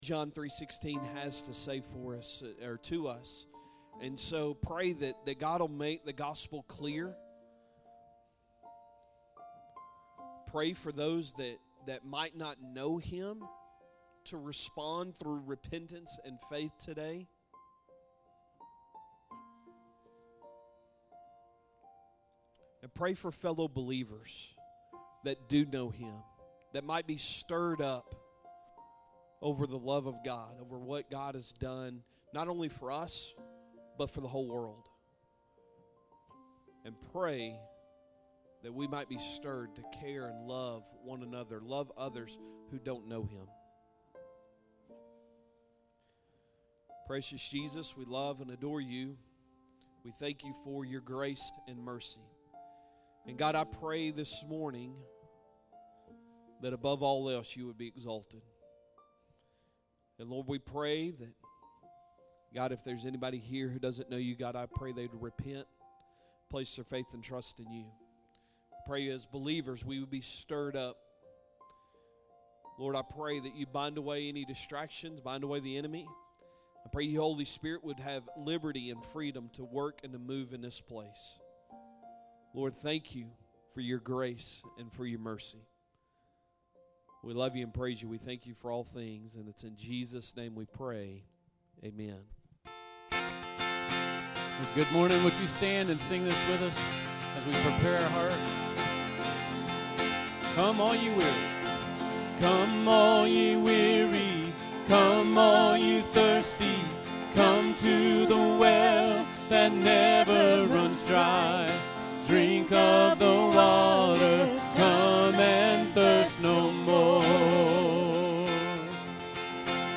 Sunday Morning Sermon March 16, 2025